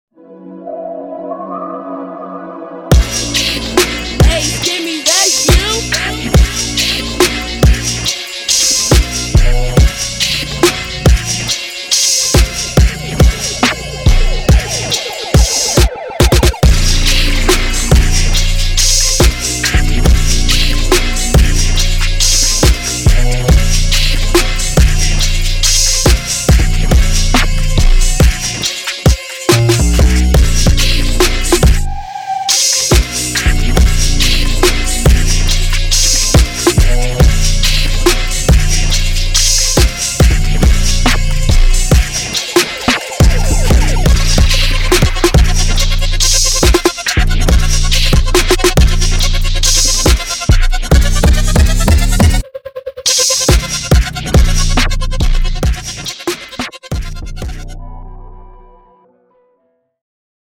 Trap
dark trap soundscape